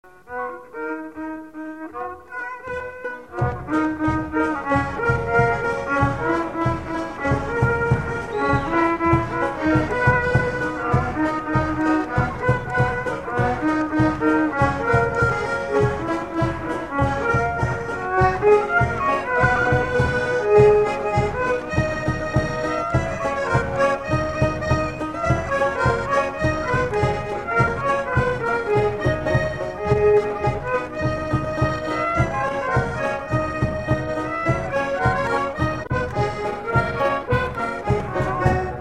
Instrumental
danse : polka
Pièce musicale inédite